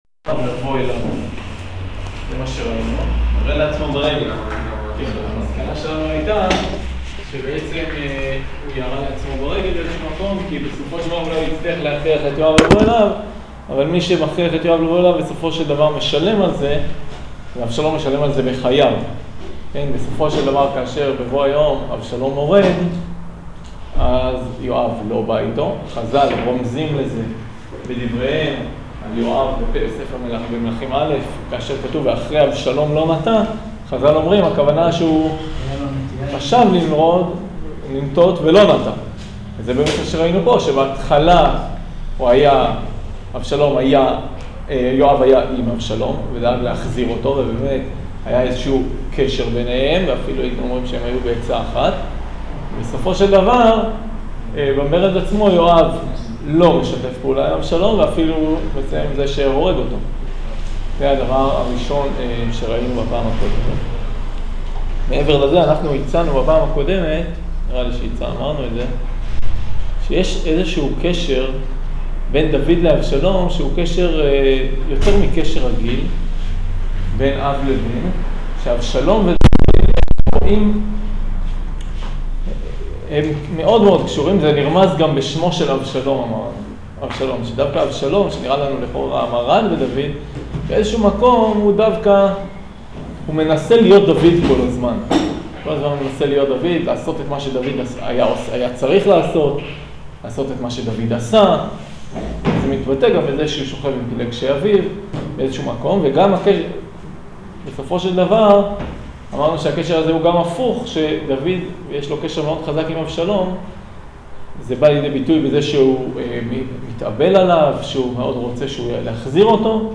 שיעור תחילת מרד אבשלום